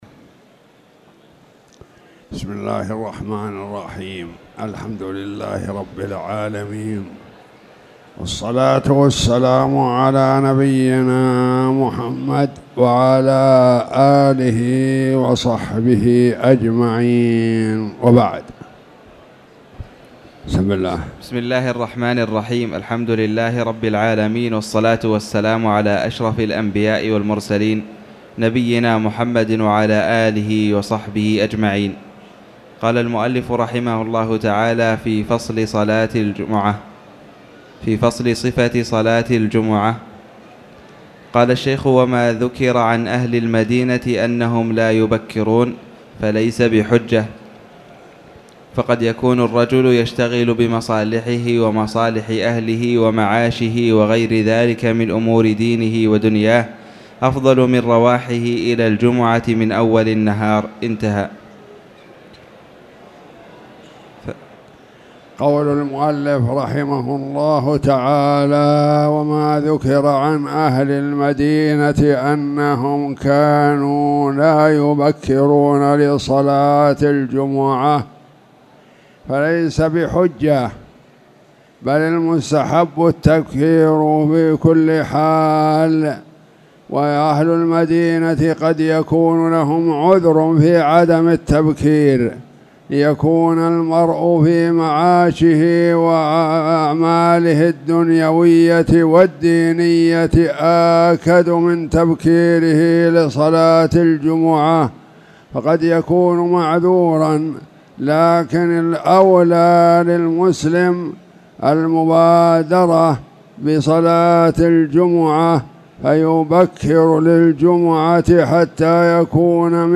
تاريخ النشر ١٤ جمادى الأولى ١٤٣٨ هـ المكان: المسجد الحرام الشيخ